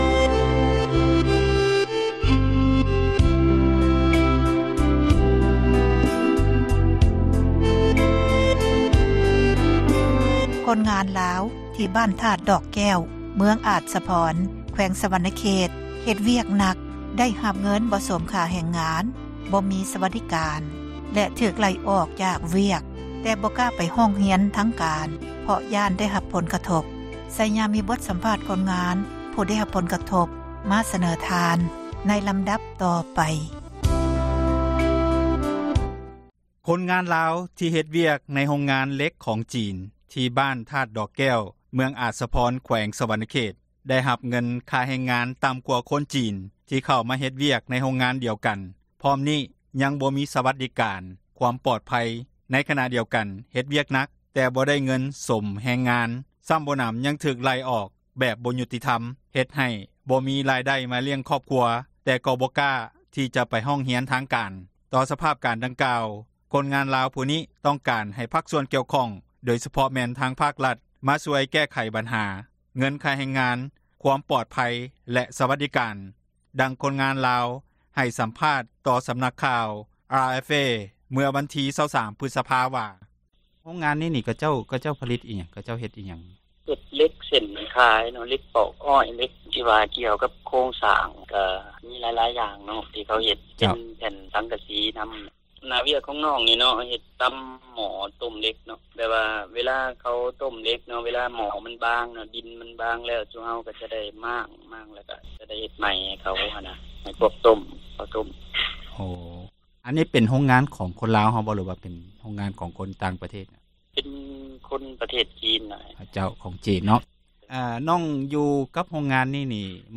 ດັ່ງຄົນງານລາວໃຫ້ສຳພາດຕໍ່ RFA ເມື່ອວັນທີ 23 ພຶສພາ ນີ້ວ່າ: ເຊີນທ່ານຮັບຟັງ ການສຳພາດ...